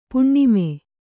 ♪ puṇṇime